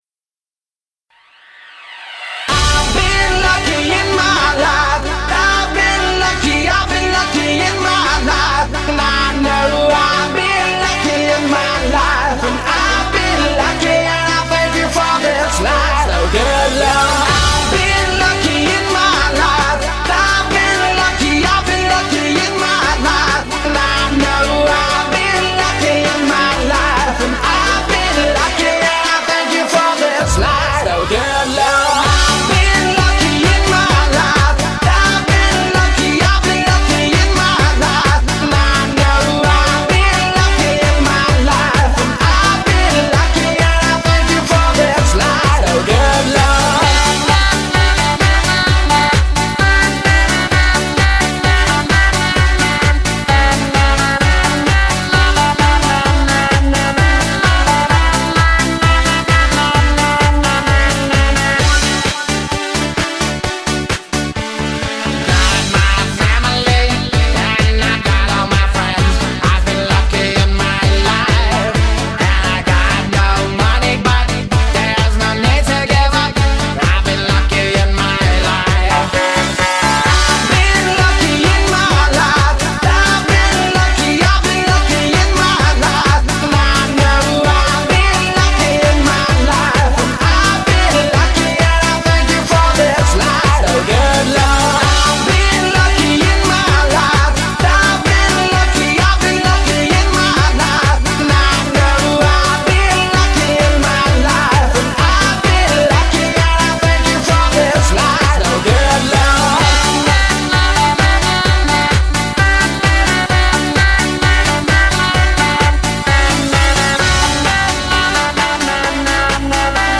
BPM130
Audio QualityMusic Cut
europop